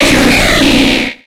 Cri de Roigada dans Pokémon X et Y.